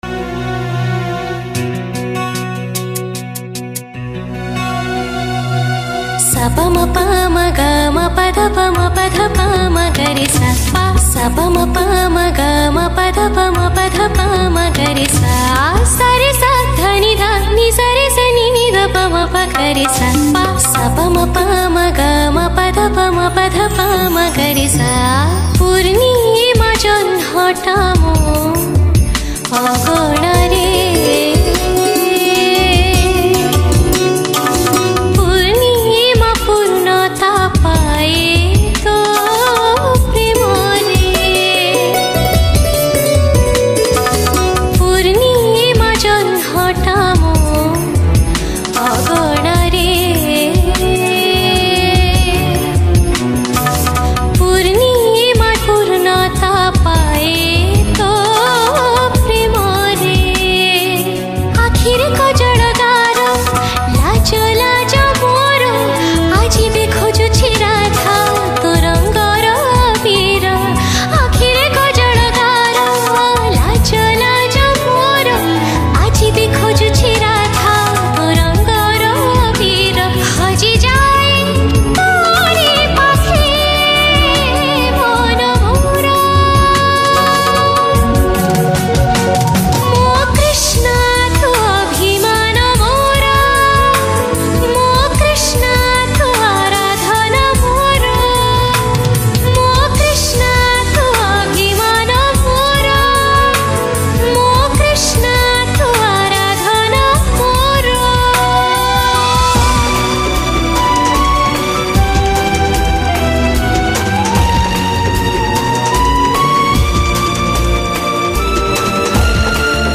Classy Romantic Odia Song
Category: Odia Bhakti Hits Songs